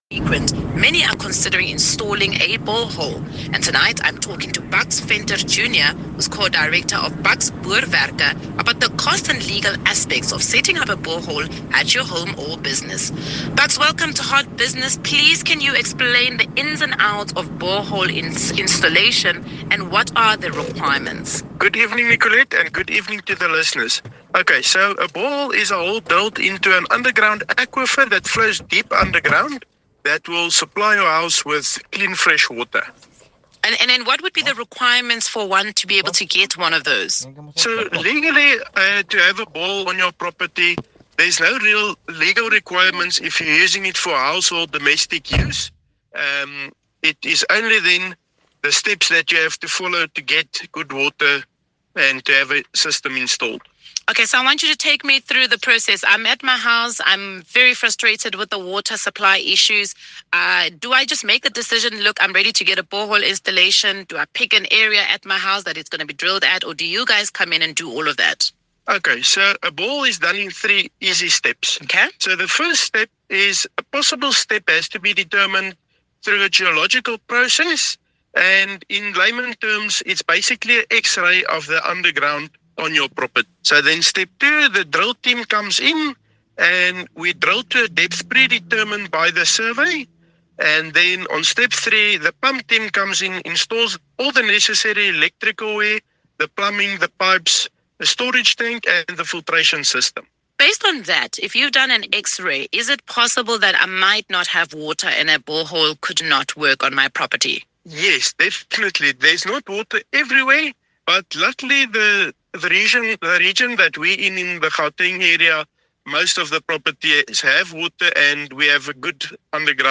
Radio Interview With 102.7 FM